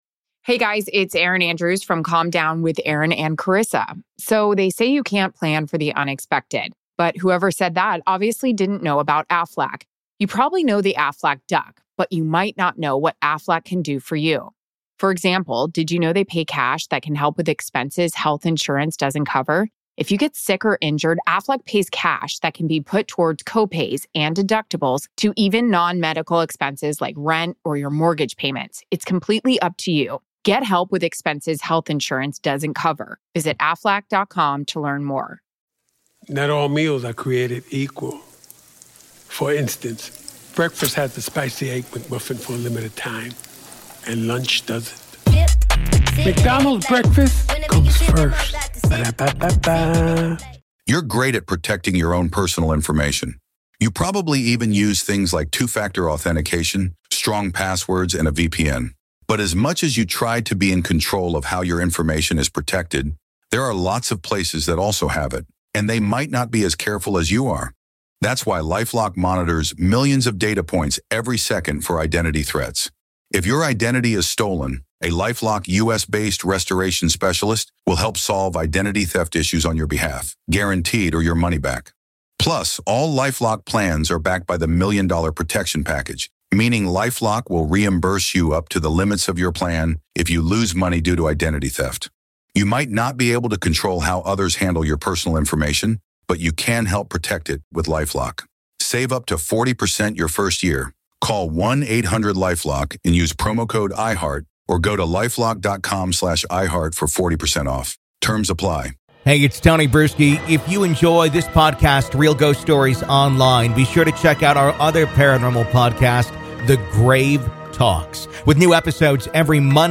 Journey To Conjuring | A Conversation